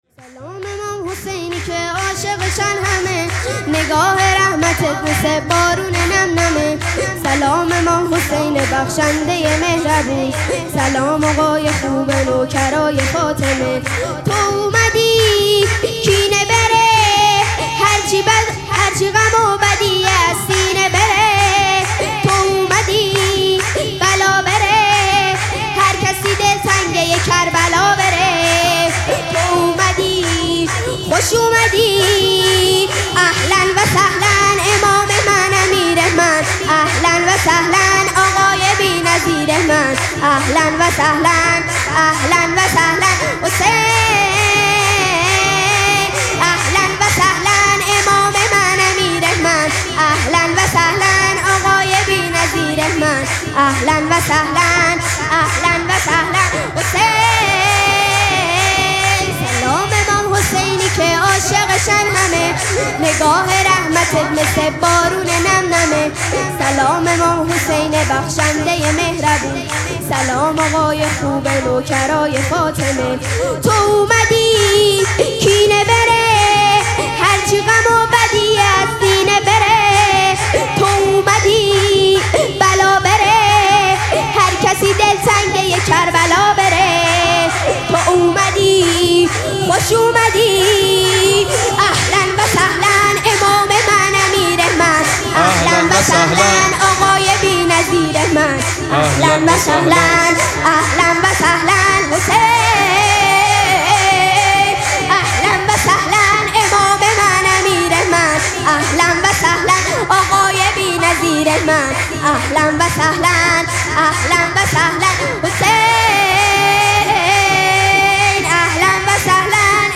شب دوم مراسم جشن ولادت سرداران کربلا
سرود